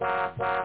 honkhonk-hi.mp3